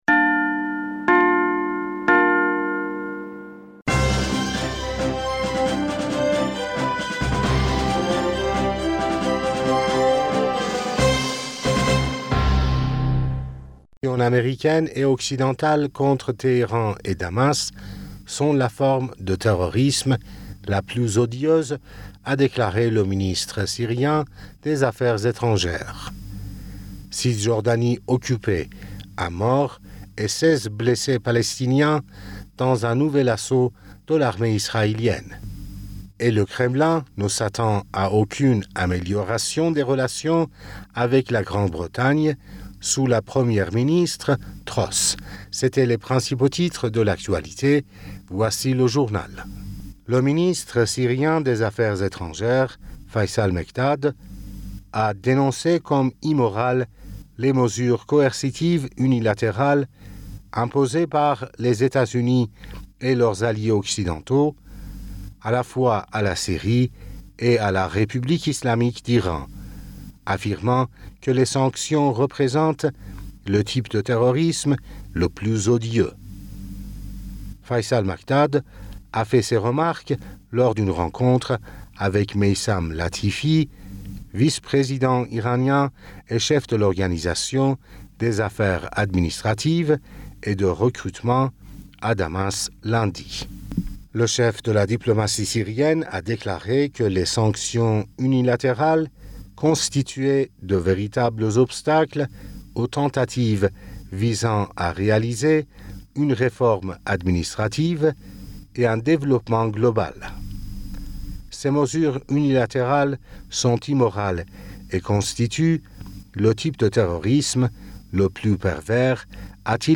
Bulletin d'information Du 06 Septembre